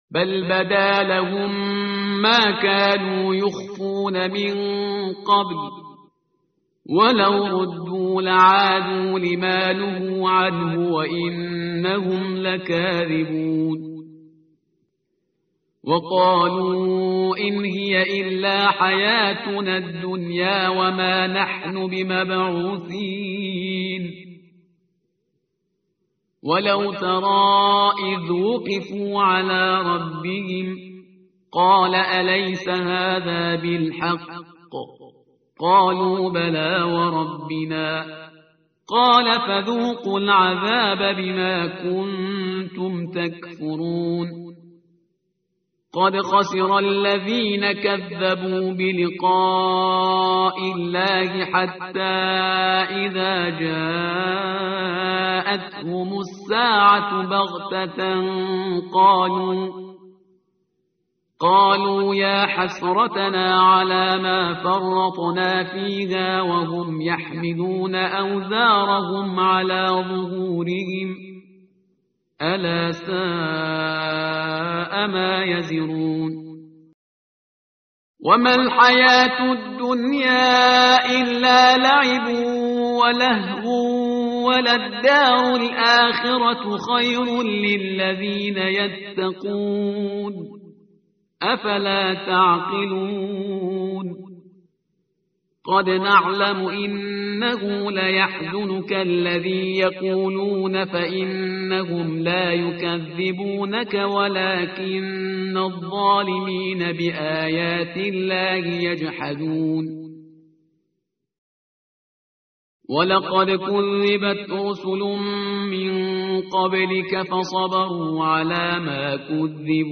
tartil_parhizgar_page_131.mp3